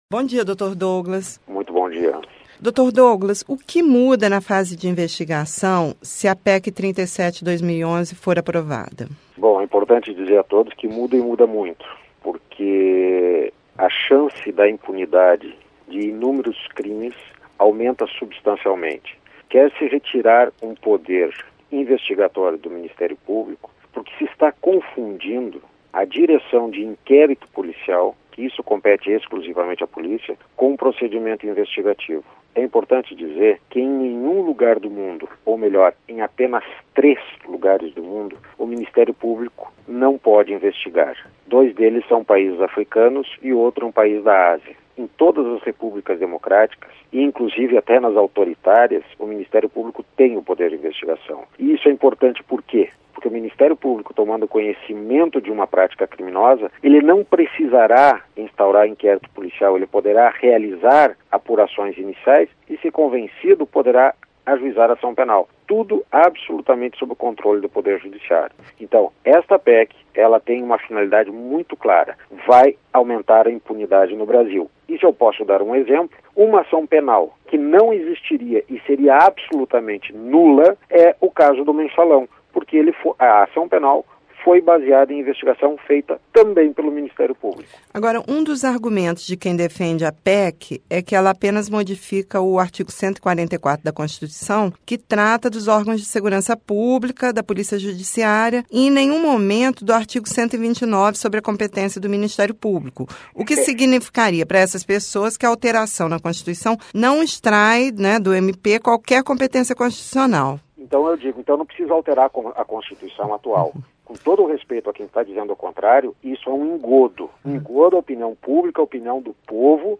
Procurador da República critica PEC que restringe poder do Ministério Público Entrevista com o procurador Douglas Fischer.